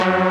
Freq-lead45.ogg